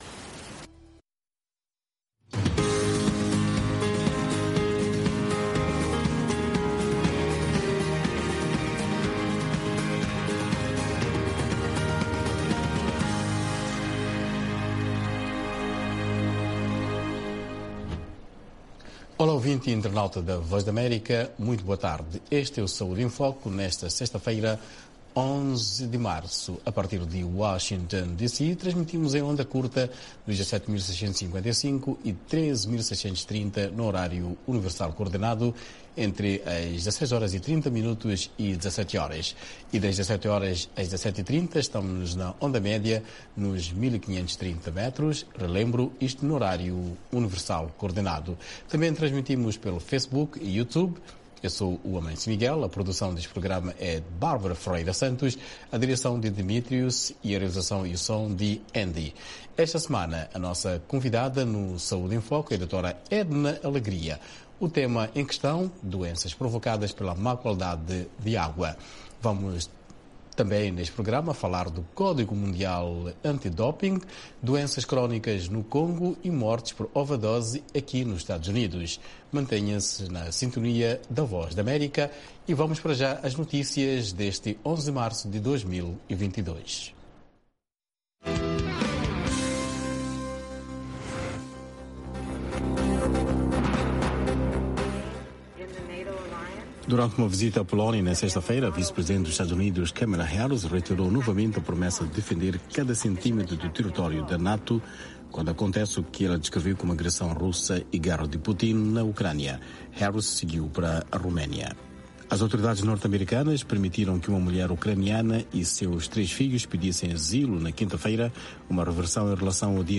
Todas as semanas falamos sobre saúde, dos Estados Unidos da América para o mundo inteiro, com convidados especiais no campo social e da saúde.